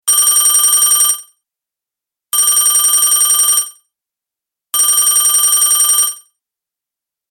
جلوه های صوتی
دانلود صدای تلفن 3 از ساعد نیوز با لینک مستقیم و کیفیت بالا